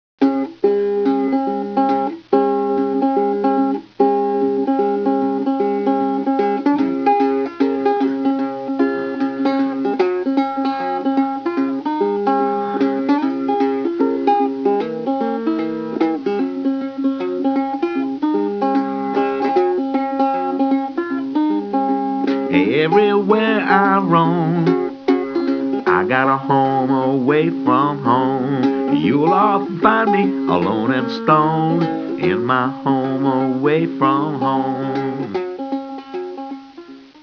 Vocals, guitar